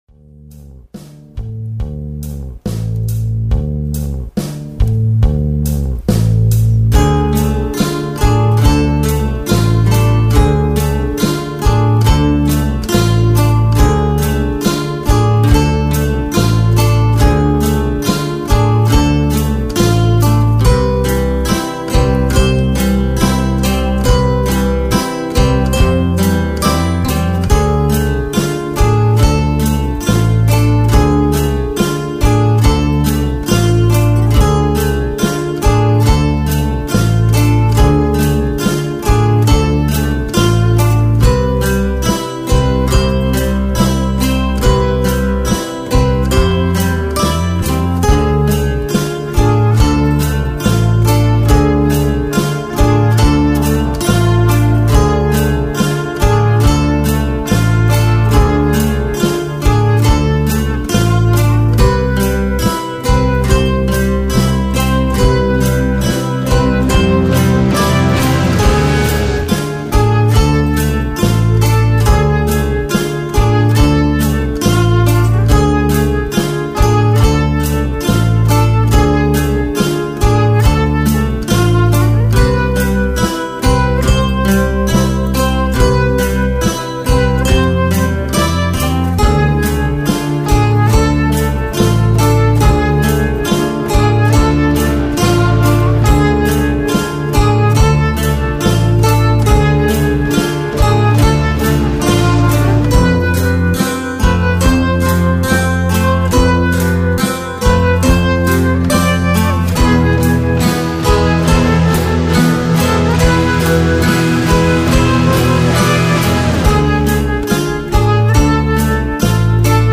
11 Free to download creepy tracks for Halloween…